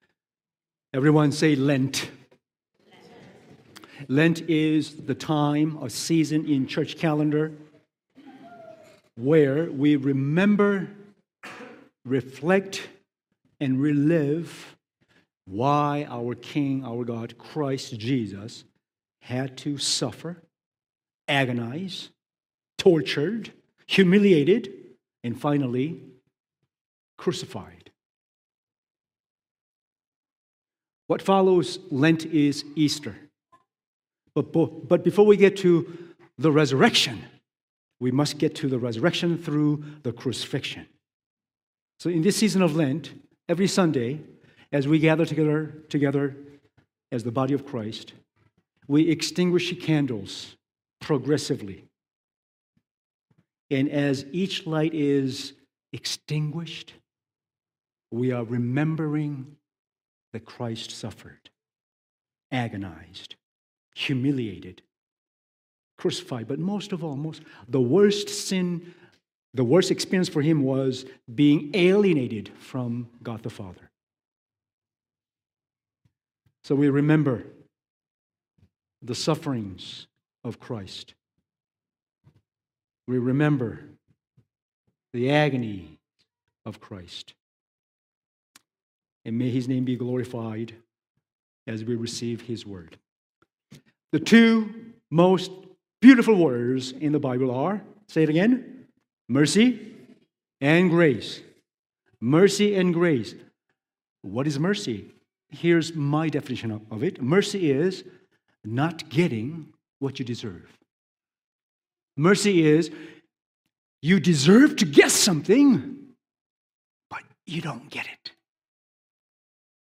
Sermons | mosaicHouse